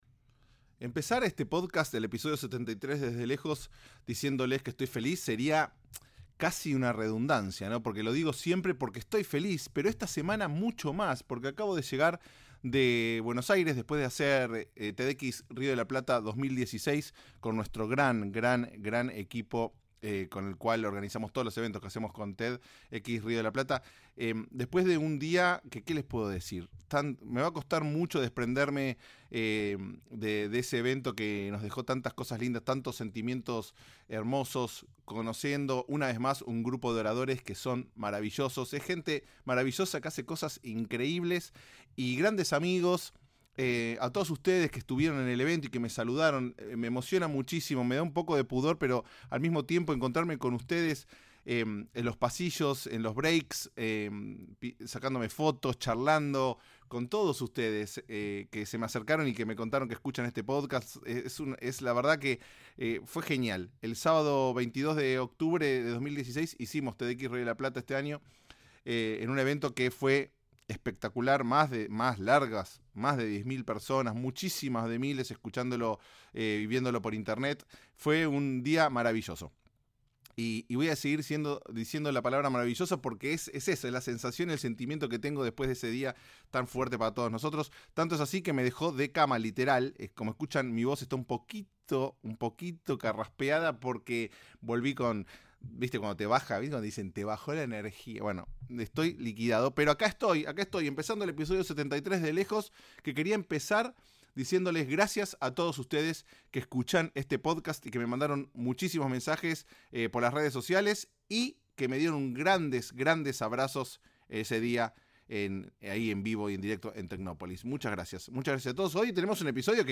Gran charla